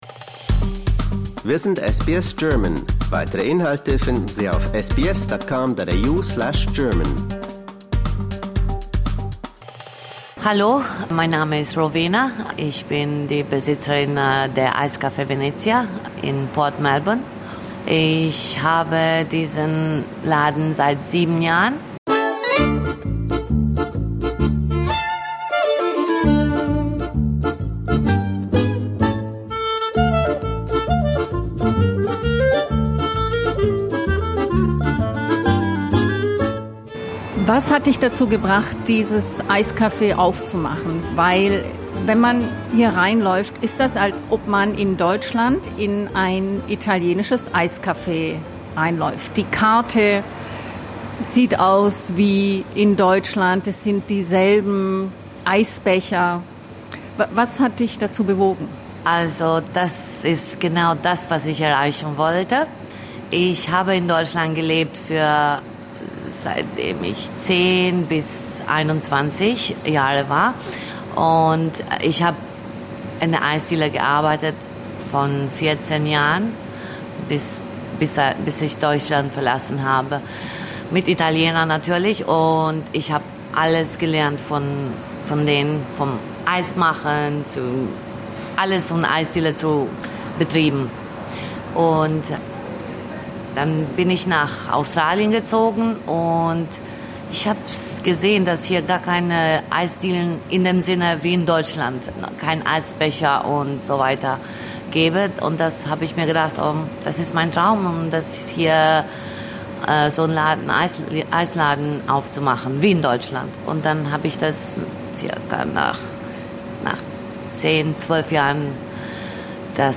We visited her in her ice cream parlour.